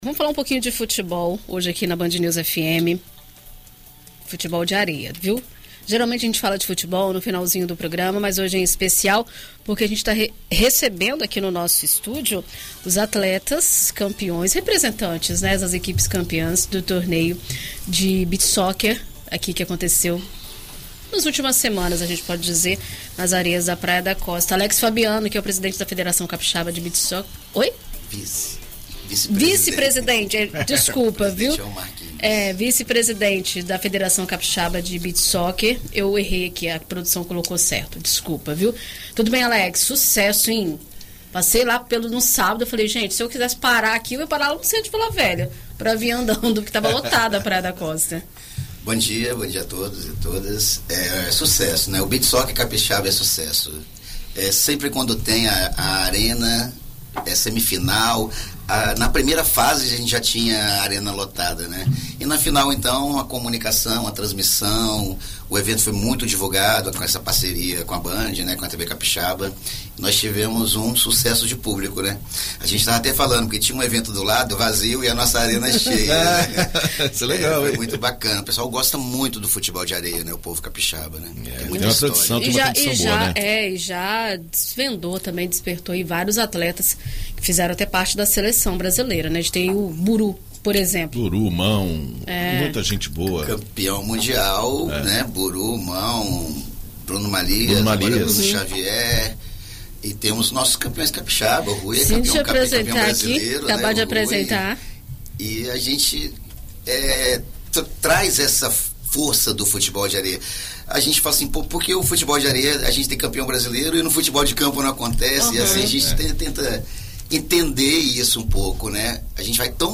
Em entrevista à BandNews FM Espírito Santo nesta segunda-feira (01)